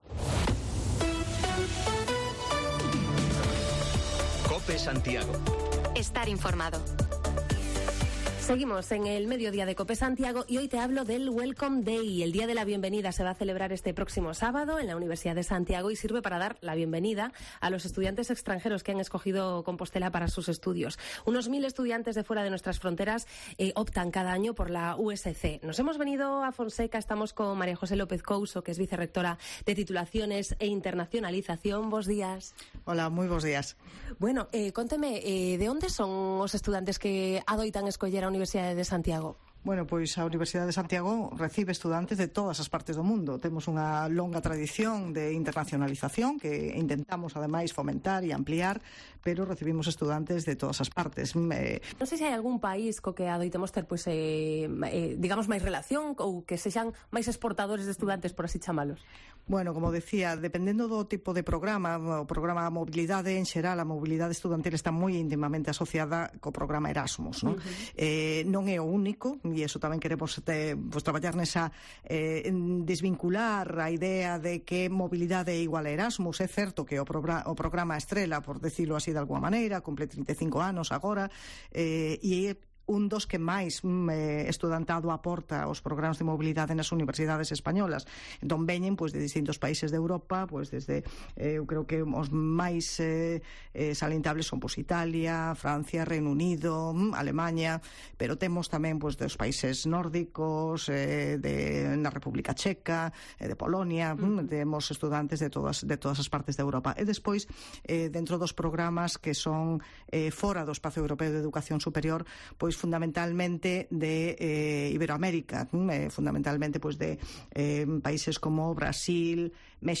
Mediodía de Cope Santiago, hoy desde la USC, para contarte cómo es la acogida a los estudiantes internacionales que cada año eligen la universidad compostelana.